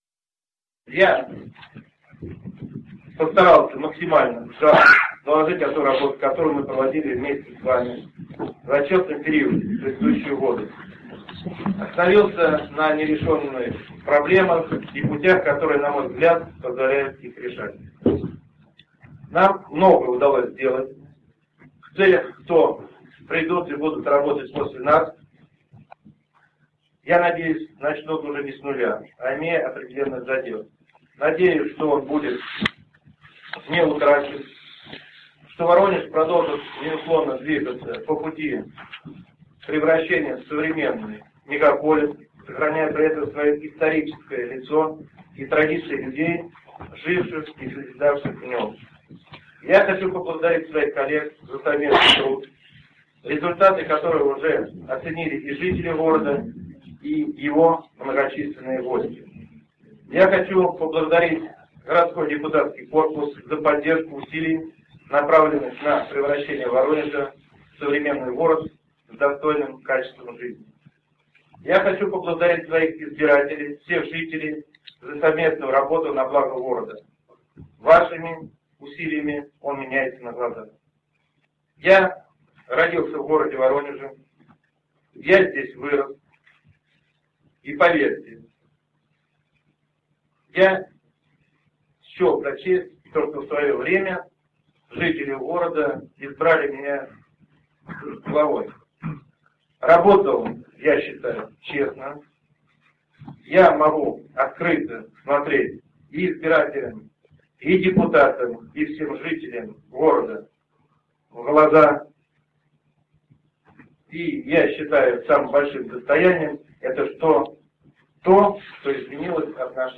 Глава города представил депутатам отчет о своей работе, в заключении которого и объявил о том, что покидает свой пост.